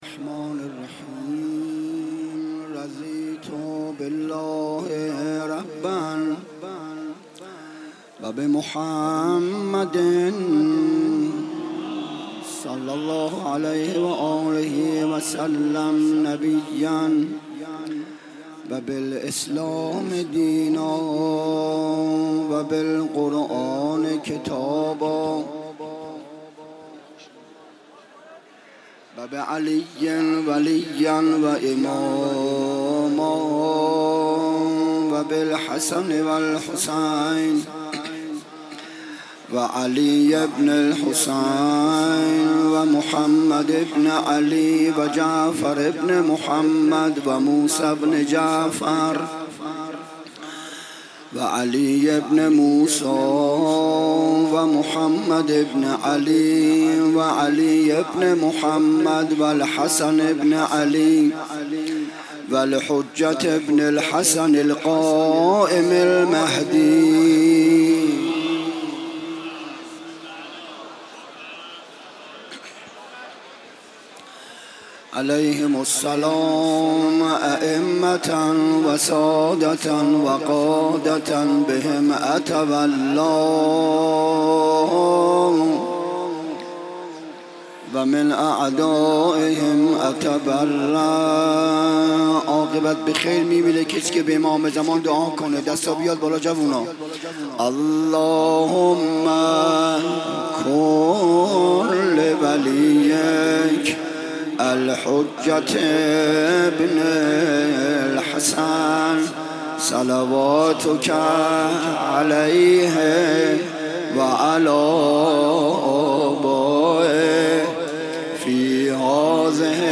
َشب سوم محرم 95_سخنراني_بخش اول